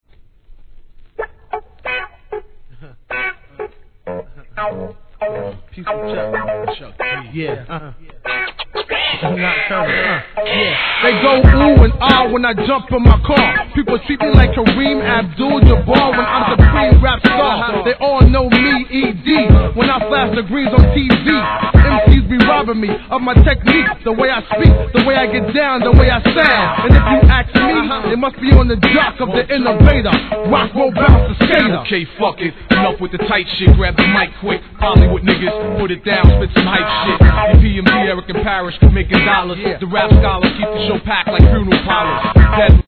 1. HIP HOP/R&B
相変わらずのFUNKサウンドのオリジナルVER.に、B/W 心地よ～いネタ感溢れるREMIX収録!!PROMO ONLY